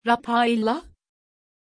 Pronunția numelui Raphaëlla
pronunciation-raphaëlla-tr.mp3